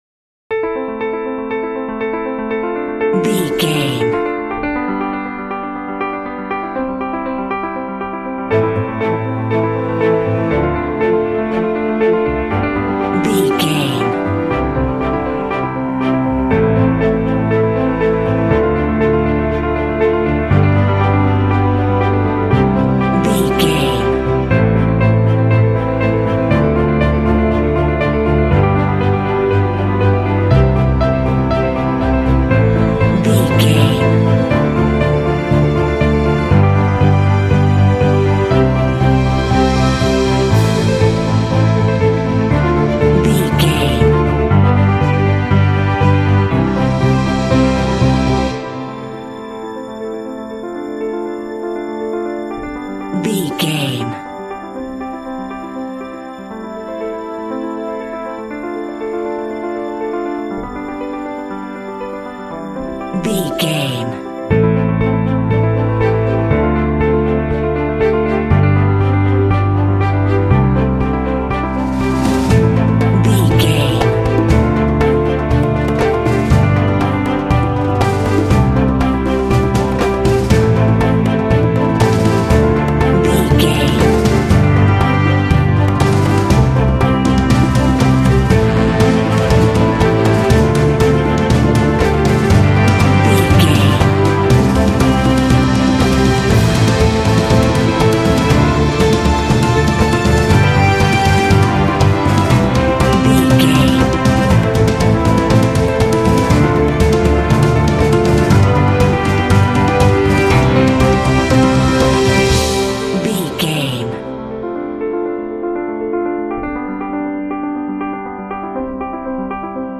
Epic / Action
Fast paced
Ionian/Major
Fast
powerful
dreamy
drums
horns
cinematic